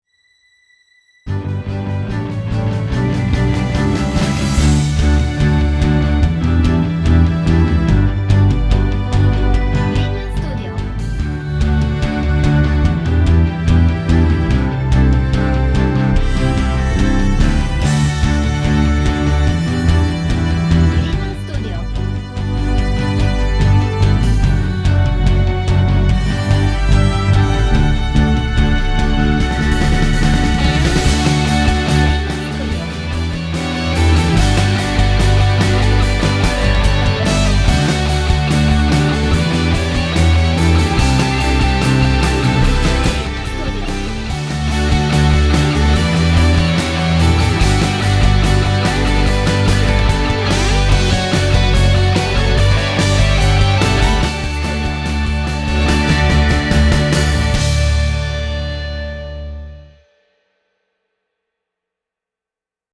Orchestral/Cinematic